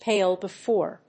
アクセントpále befòre [besìde]…